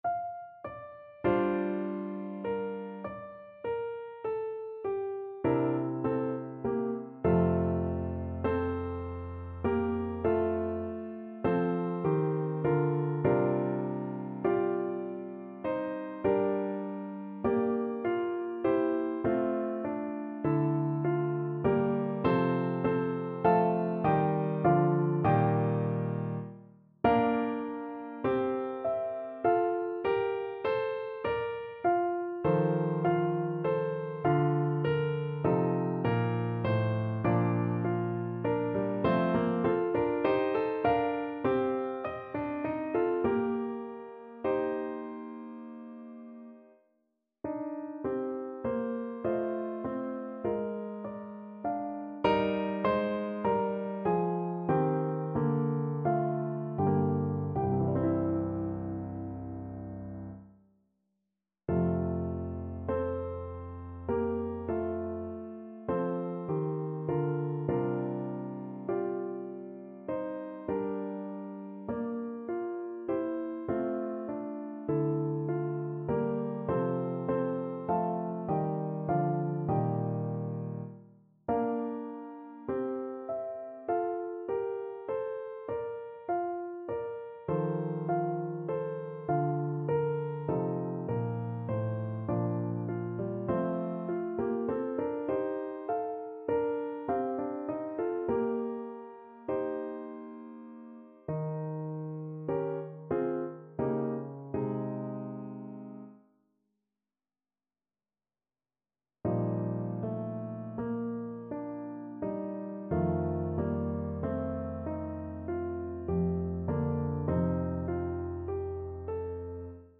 5/4 (View more 5/4 Music)
F major (Sounding Pitch) (View more F major Music for Voice )
~ = 100 Andante con moto, molto teneramente (View more music marked Andante con moto)
Classical (View more Classical Voice Music)